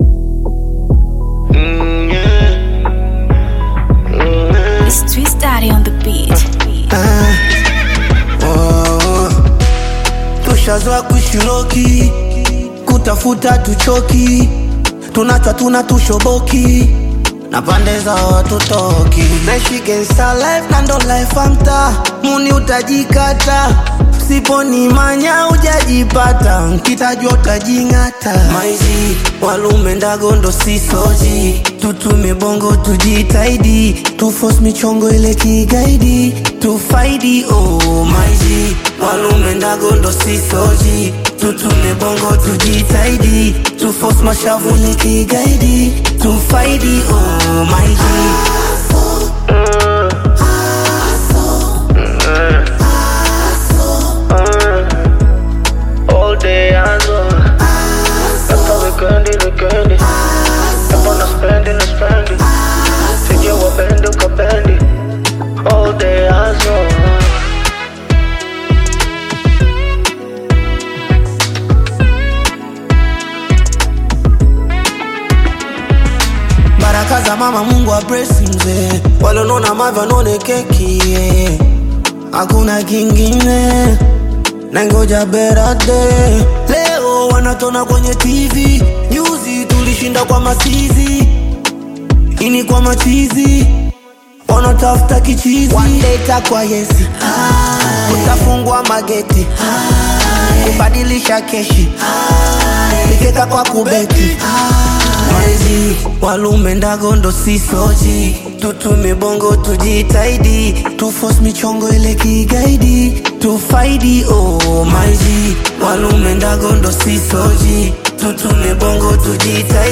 Bongo Flava music track
This catchy new song